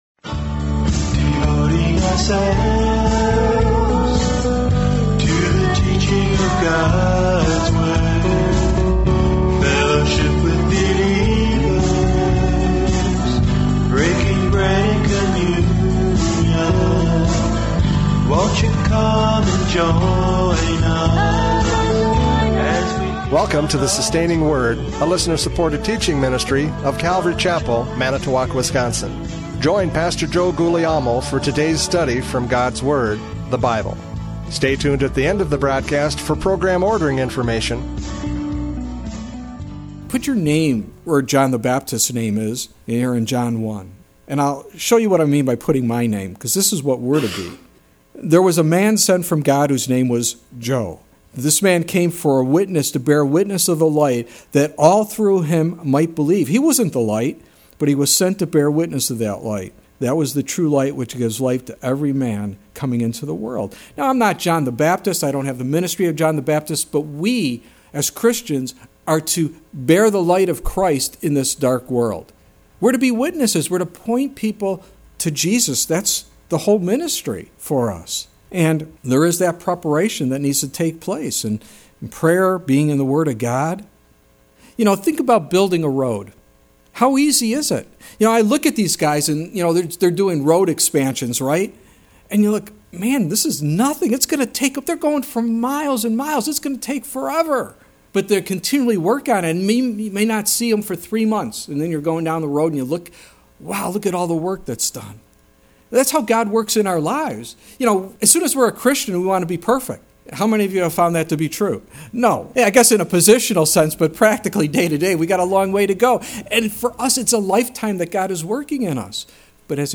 John 1:6-13 Service Type: Radio Programs « John 1:6-13 The Witness and Response!